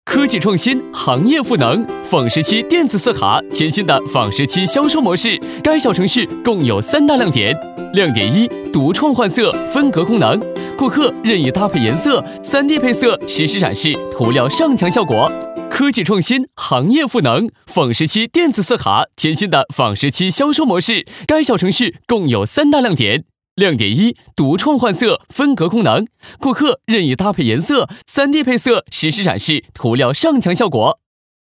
年轻时尚 MG动画